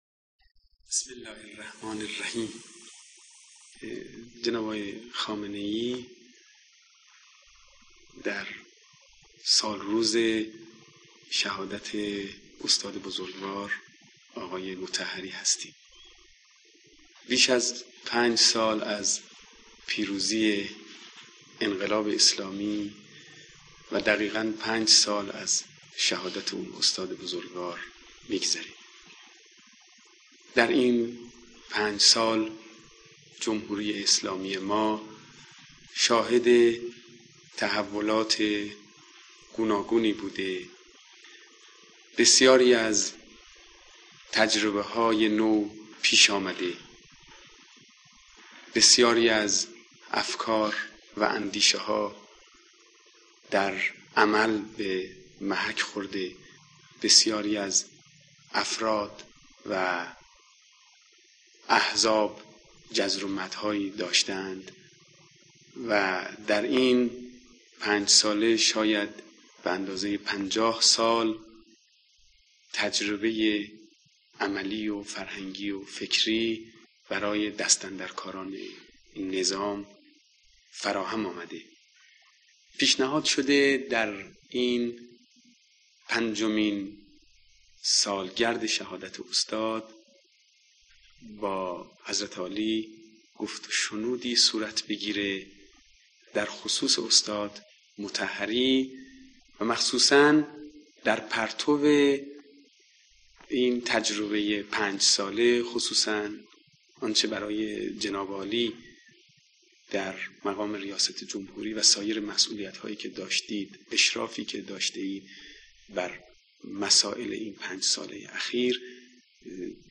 مصاحبه آقای حداد عادل با رهبر انقلاب پیرامون شخصیت استاد مطهری